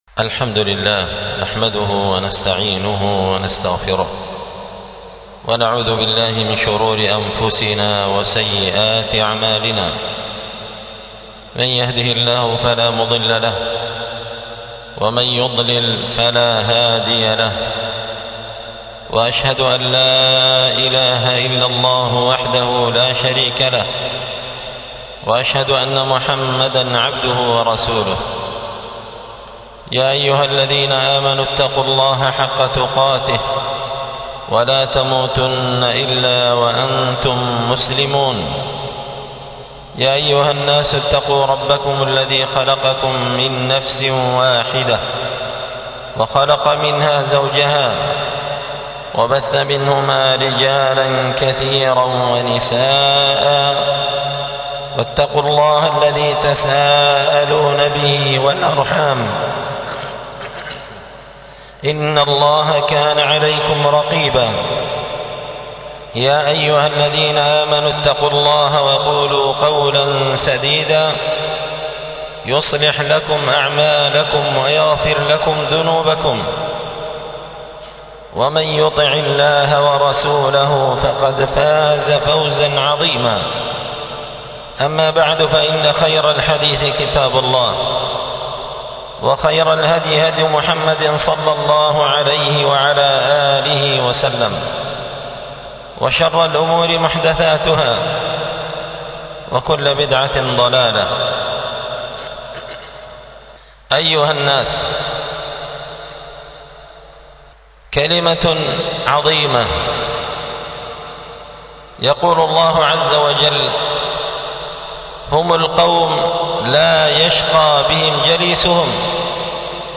ألقيت هذه الخطبة بدار الحديث السلفية بمسجد الفرقان قشن-المهرة-اليمن تحميل